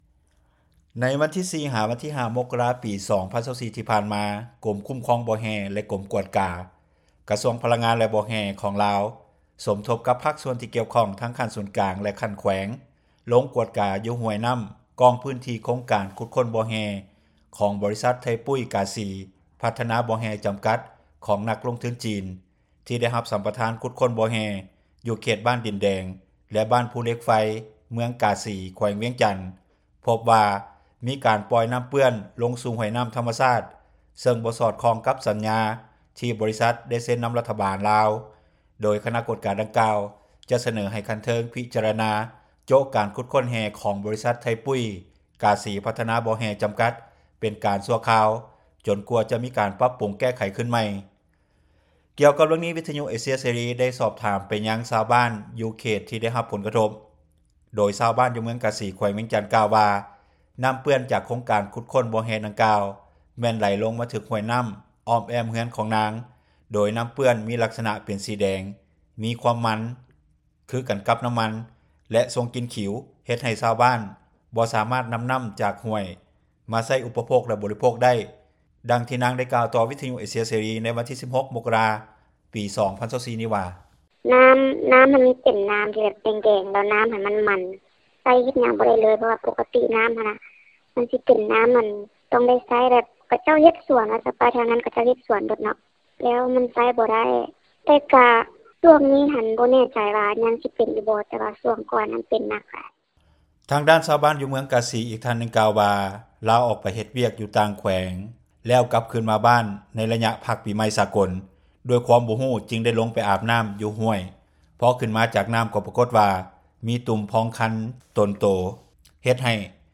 ດັ່ງທີ່ນາງກ່າວຕໍ່ ວິທຍຸເອເຊັຽເສຣີ ໃນມື້ວັນທີ 16 ມົກກະຣາ 2024 ນີ້ວ່າ:
ດັ່ງທີ່ນາງ ກ່າວວ່າ: